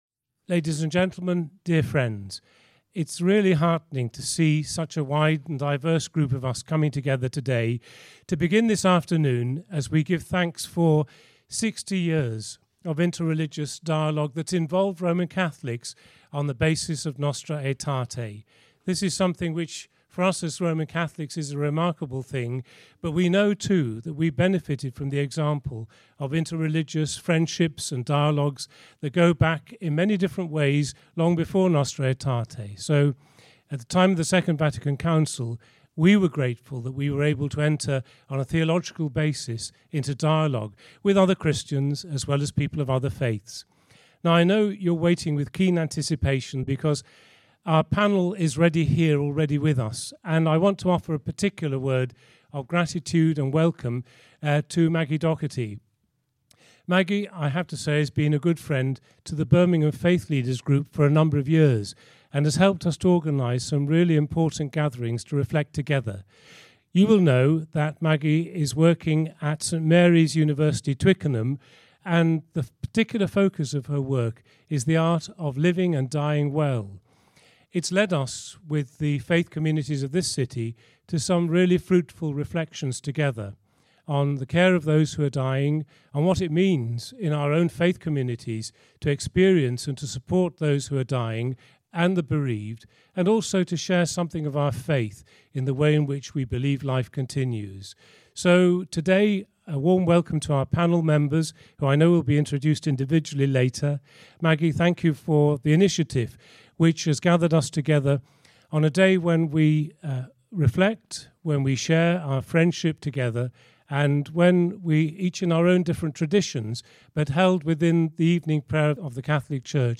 ‘Dying to Live’ was a panel discussion that took place in the Grimshaw Room of St Chad’s Cathedral, Birmingham, on Sunday 26 October.